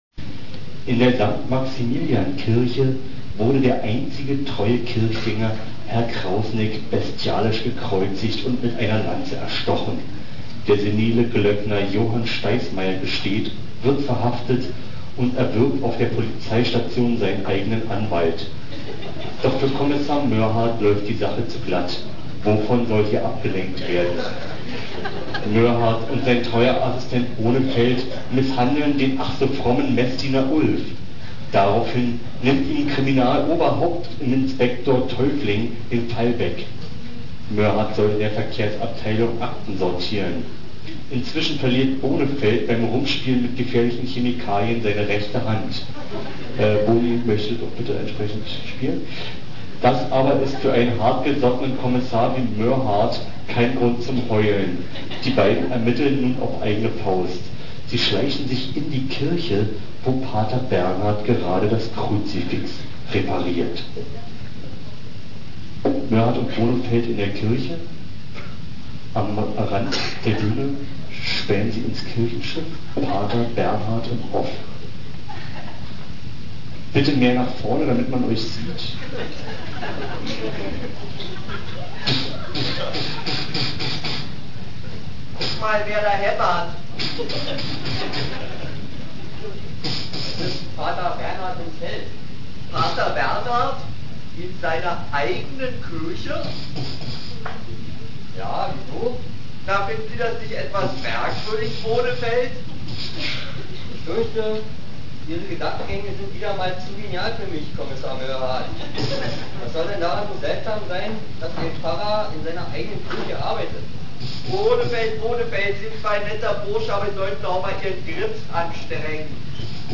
Sounds: H�mmerger�usche, Anfahrger�usch, Z�ndung